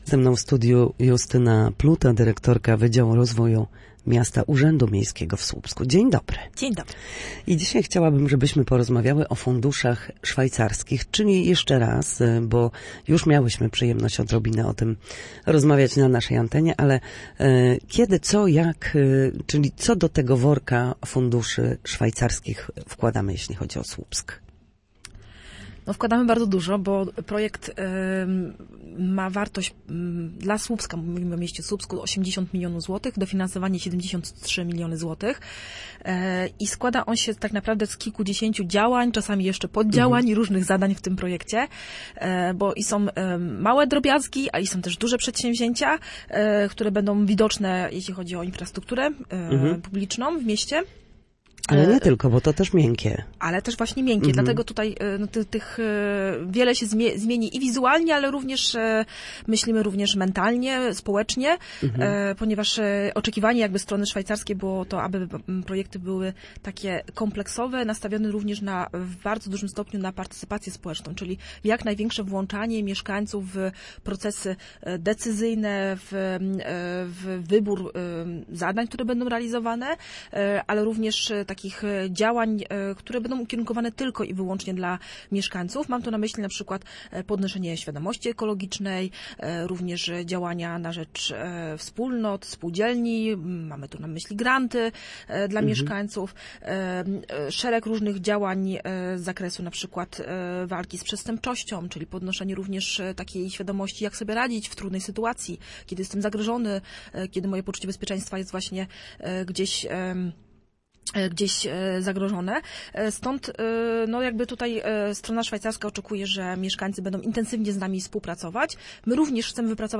Na naszej antenie mówiła o Funduszach Szwajcarskich, dzięki którym Słupsk rozpoczyna realizację działań poprawiających jakość życia mieszkańców i zwiększających bezpieczeństwo w mieście. Pierwsze prace rozpoczną się w tym roku.